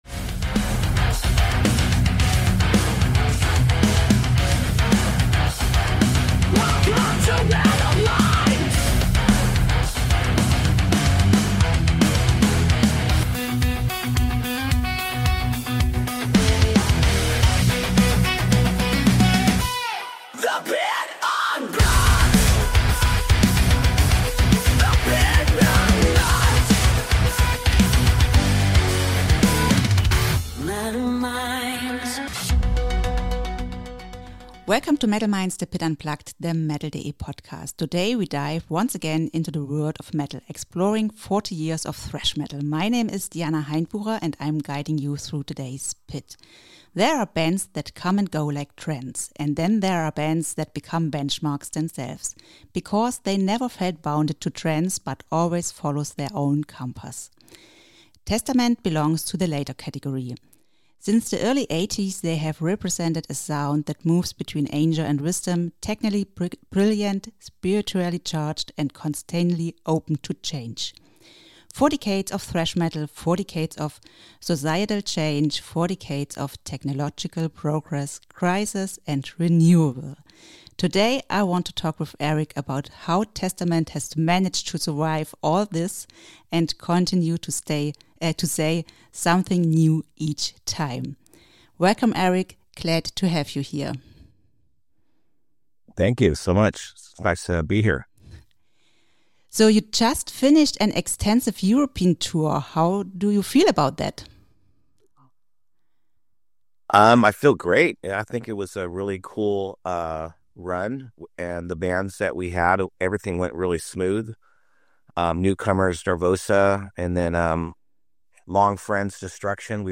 Ein offenes, überraschend pragmatisches Gespräch über Musikmachen im Hier und Jetzt, ohne Pathos, mit Substanz.